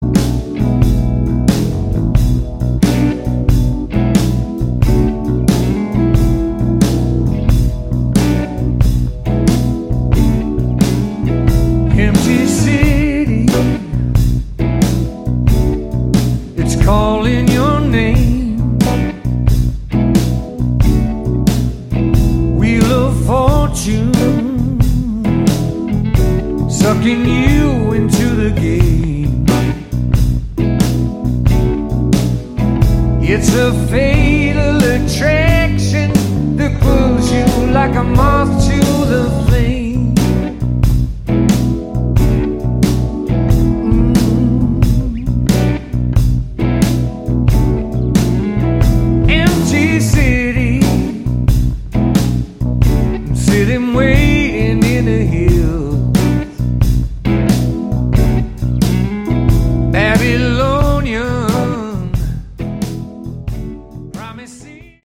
Category: Hard Rock
guitar, keyboards, backing vocals